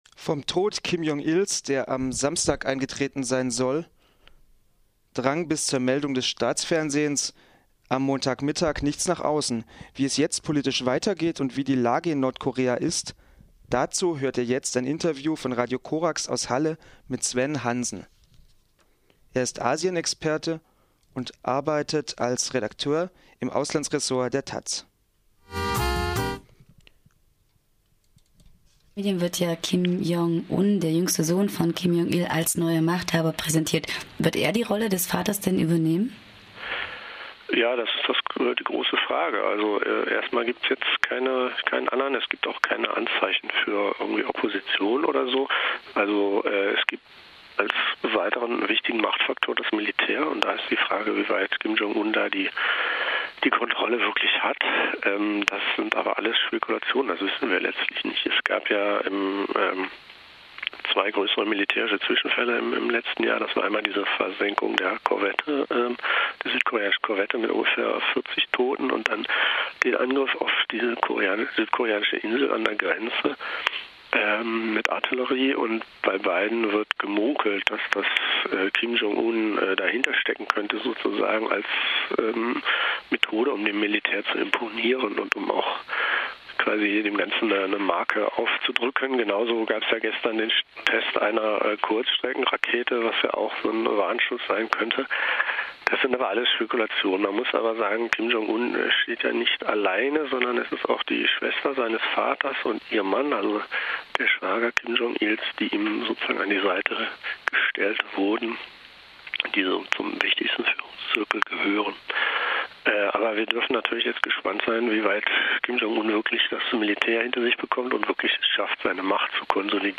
Wie geht es jetzt in Nordkorea weiter? - Telefon-Interview